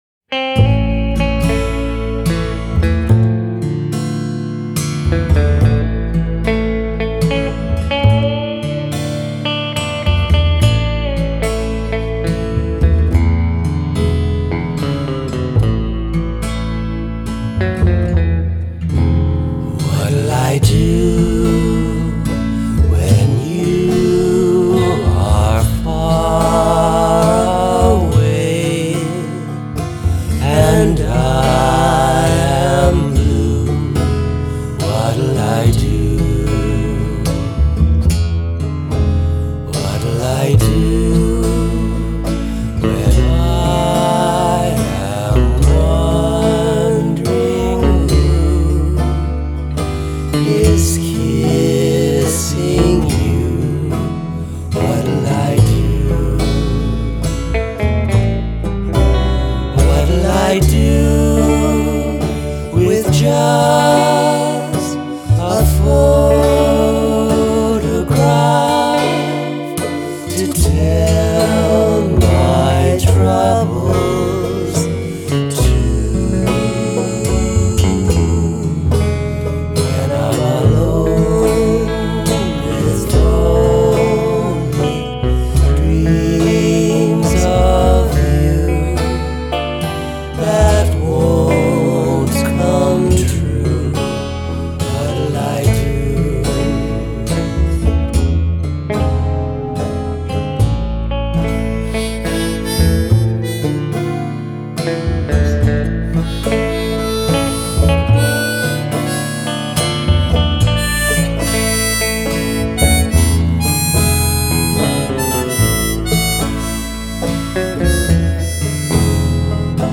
vocals/harmonica
vocals/guitars/banjo/piano/tambourine
bass/drums